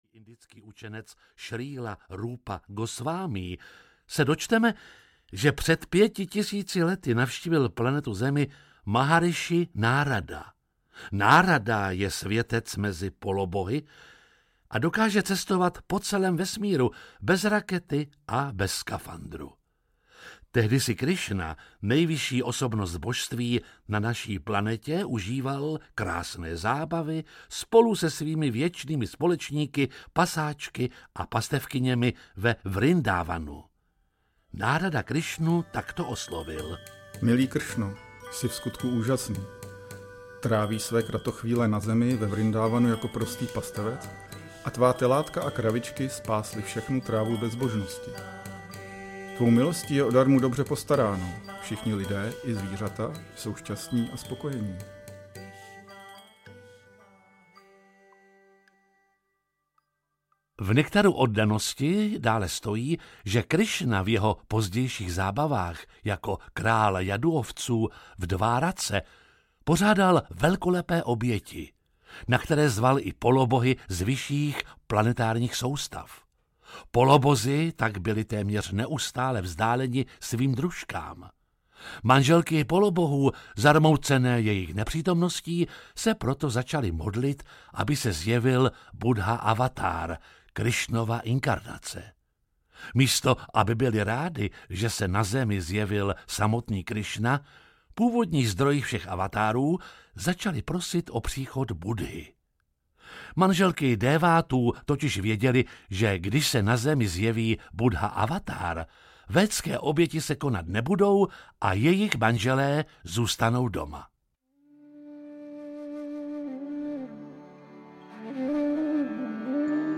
Krásný Bhágavatam díl 6. audiokniha
Ukázka z knihy